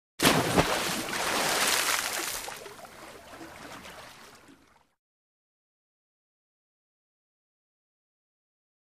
Large Splash Or Dive Into Water 5.